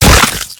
sling_hit.ogg